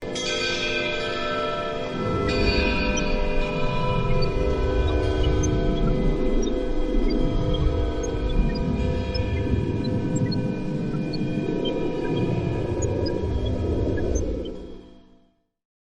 Free Nature sound effect: Solar Wind Chime.
Solar Wind Chime
Solar Wind Chime.mp3